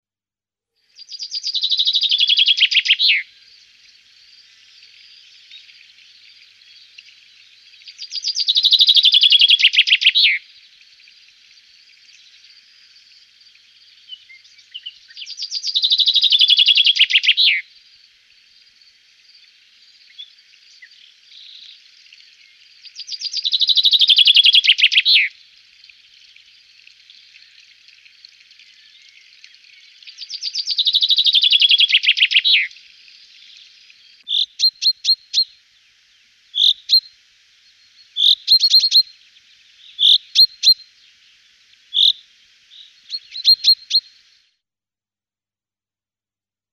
Pinson des arbres
Nom scientifique : Fringilla coelebs
Chant :
Le Pinson des arbres fringote, ramage, siffle. Le cri habituel du pinson des arbres est un net "pink-pink". Le cri d'alarme est un ténu "seee". On peut aussi entendre un "hweet" demandeur, et un bas "choop-choop" en vol dans les groupes d'hivernants.
Son chant est une série courte et puissante de notes descendantes, finissant en fioritures "chip-chip-chip-chip-chett-chett-chett-chett-diddip-diddiooo", avec beaucoup de variations. Il existe des dialectes régionaux.
86Chaffinch.mp3